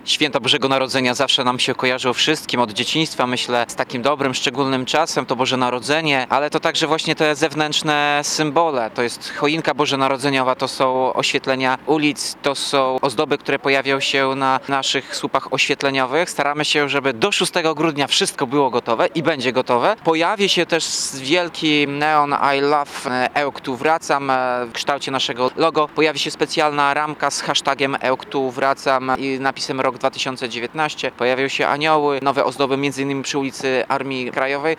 – Choinki, świecące anioły, lampiony pomagają tworzyć nastrój zbliżających się świąt – mówi Tomasz Andrukiewicz, prezydent Ełku.